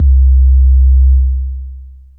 MB Kick (20).WAV